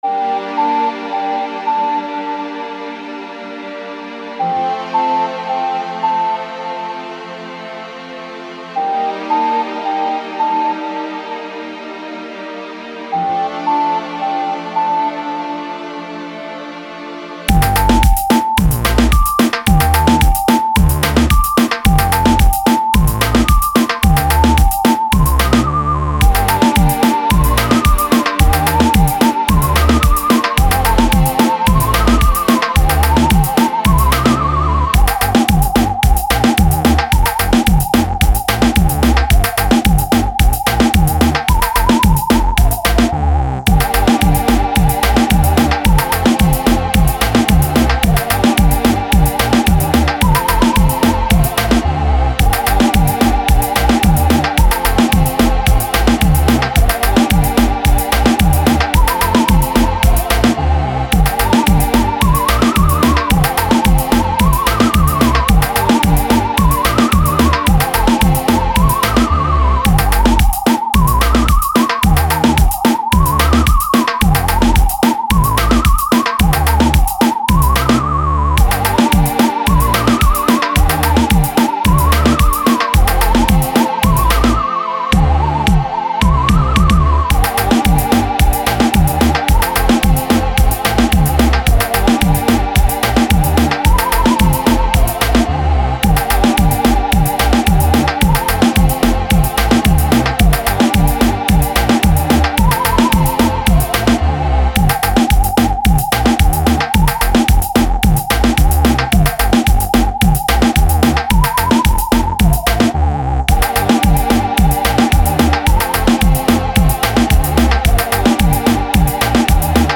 Home > Music > Latin > Bright > Laid Back > Restless